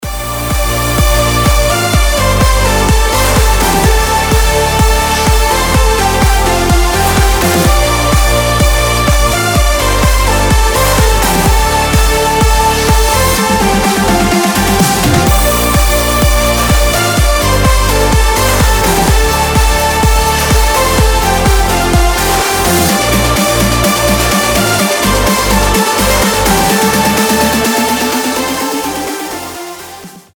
громкие
dance
Electronic
EDM
без слов
progressive house
бодрые